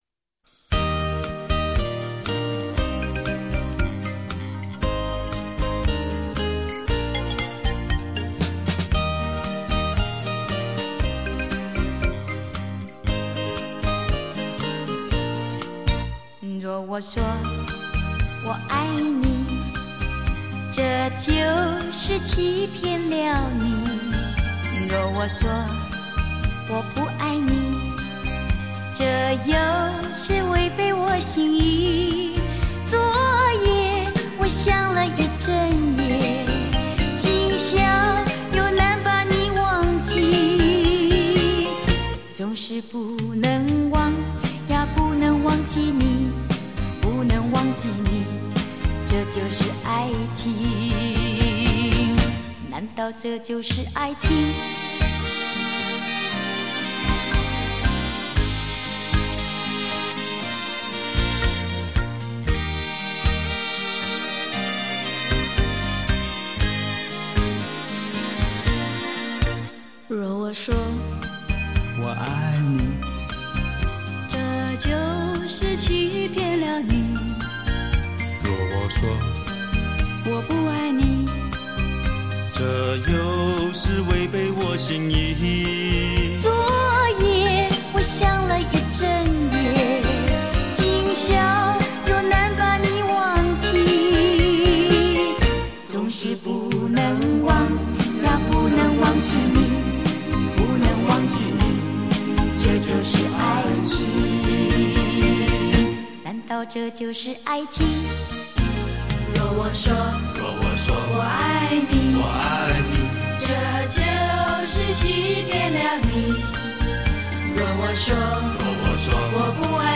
曲风包罗万像 编曲流畅，优美曼妙的歌声，让人沉浸在回忆的长河里…